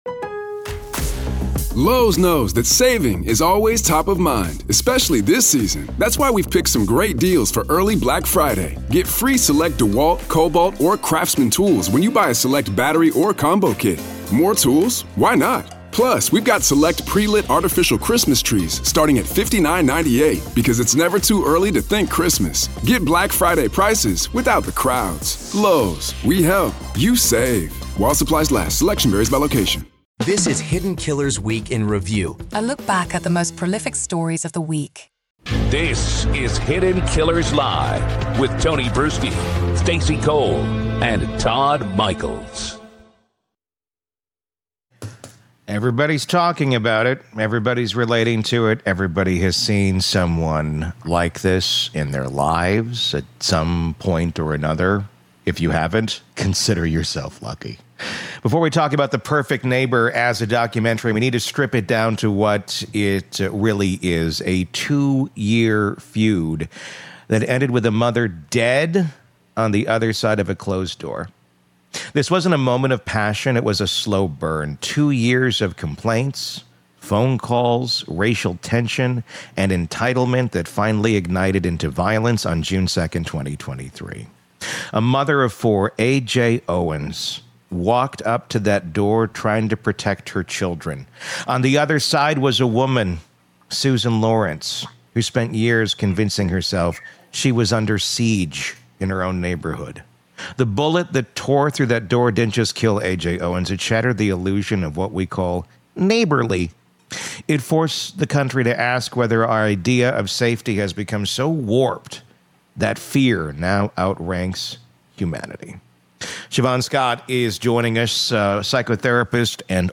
She breaks down how fear, when reinforced over years, becomes not a response but an identity. The discussion turns toward healing: what happens to children who witness or lose parents to violence, what recovery actually looks like, and how society can stop mistaking grievance for danger.